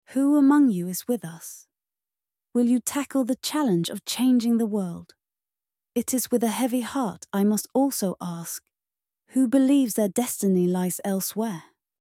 Typhoid-Mary-Mallon-Plague-Nurse-Voice.mp3